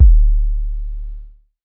sub_1.wav